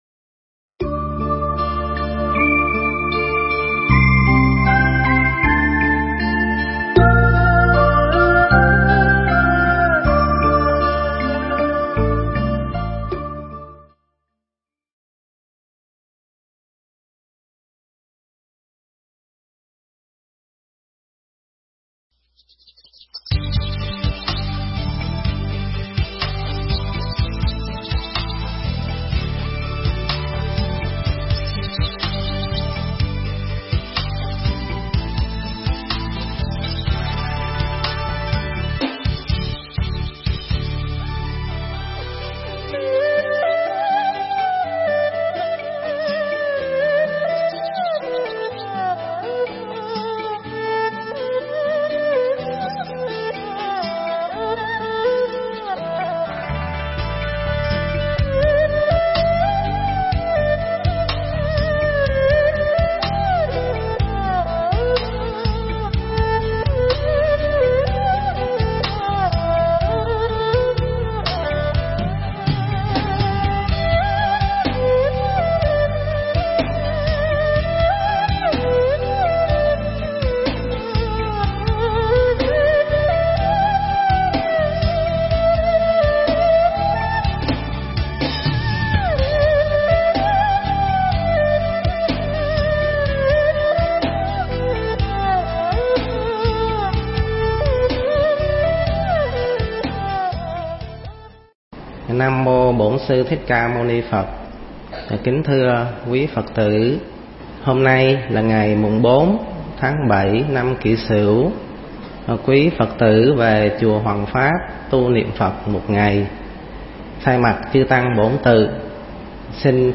Nghe Mp3 thuyết pháp Cúng Dường
Nghe mp3 pháp thoại Cúng Dường